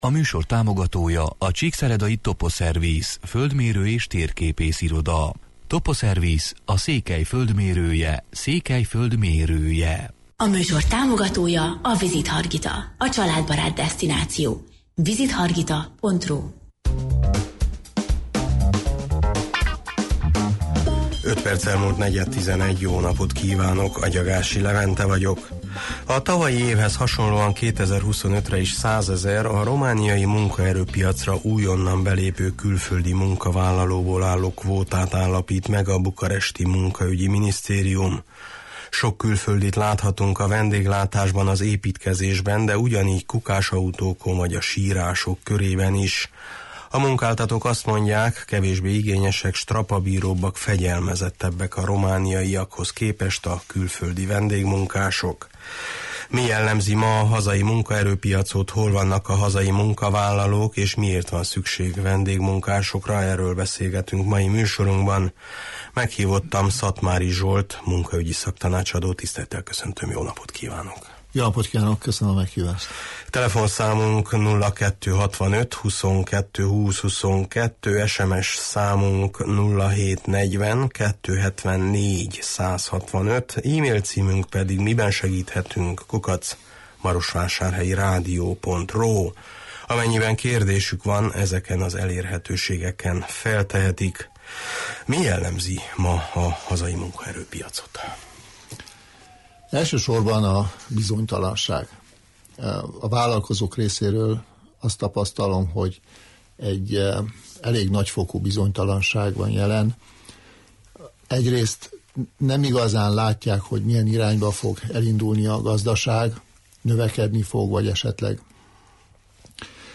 Mi jellemzi ma a hazai munkaerőpiacot, hol vannak a hazai munkavállalók és miért van szükség vendégmunkásokra? – erről beszélgetünk mai műsorunkban.